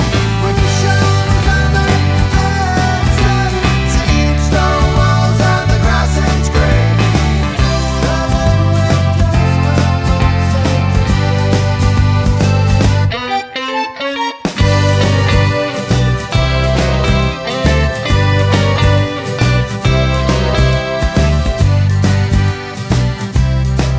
no Backing Vocals Irish 4:35 Buy £1.50